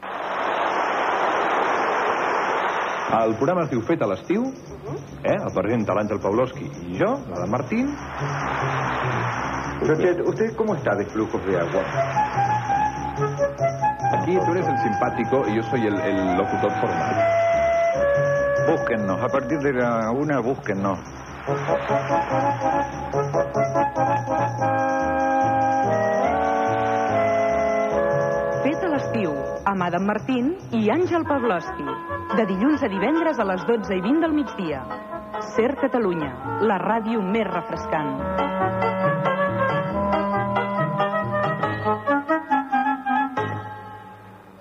Promo del programa